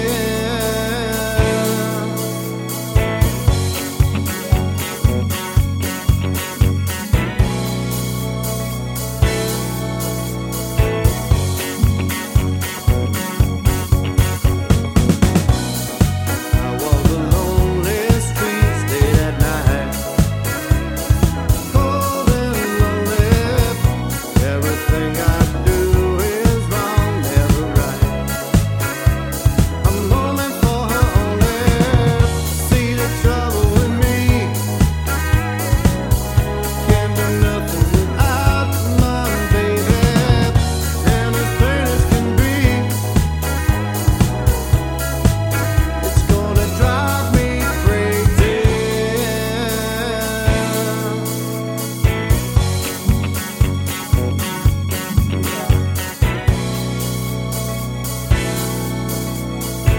no Backing Vocals Disco 2:49 Buy £1.50